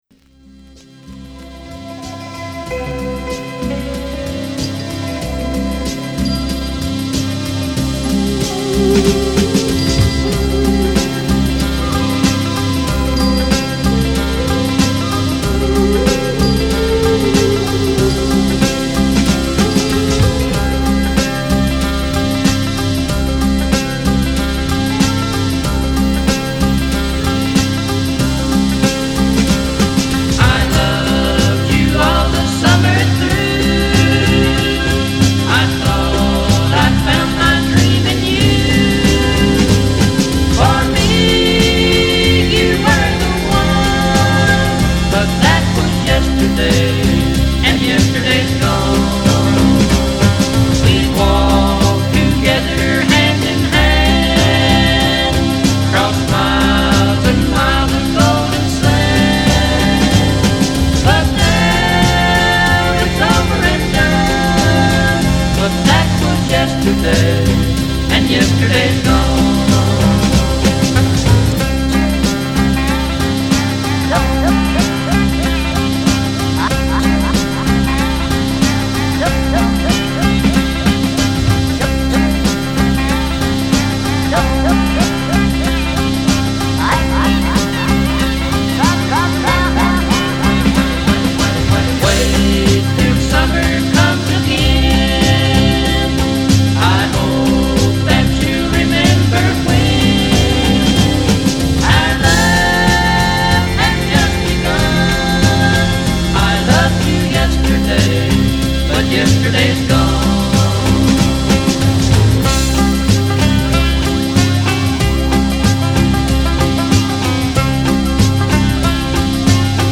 psychedelic road trip down the American highways
Bakersfield Sound of country music from the 50s & 60s
The meticulous construction